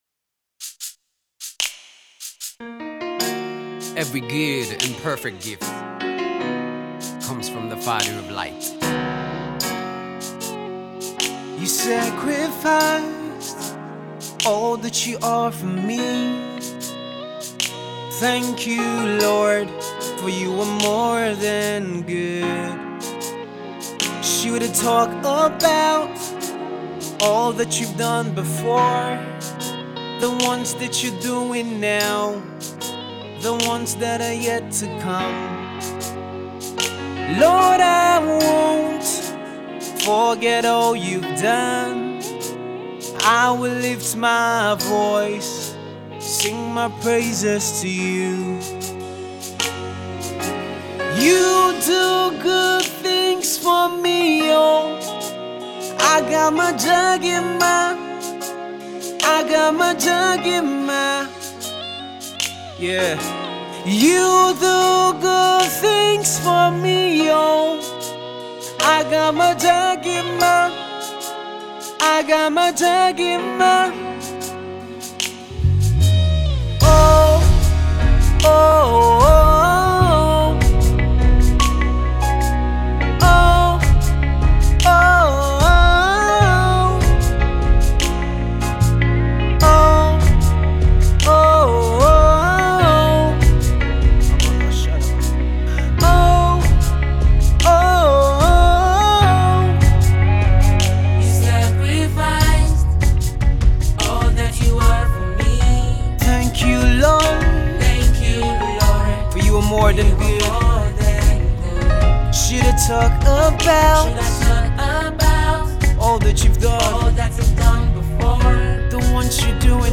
contemporary gospel
classic worship